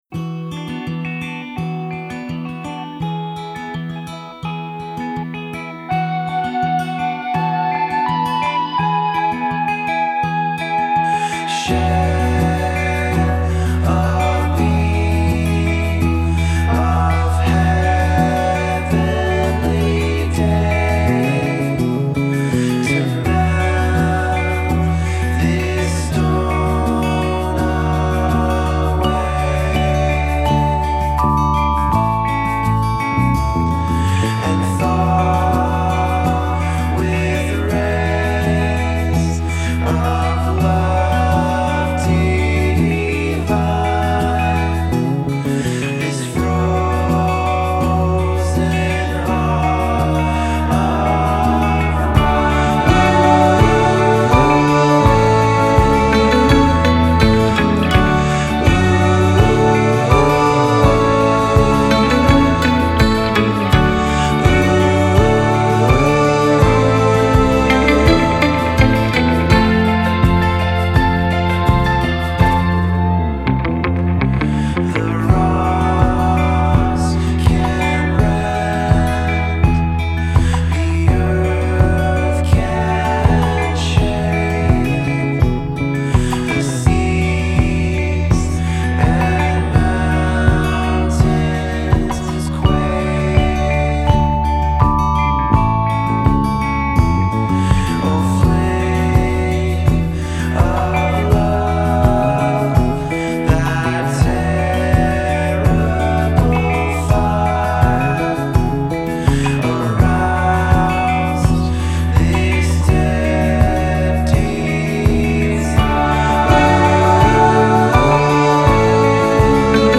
Genre: Alternative, Indie Rock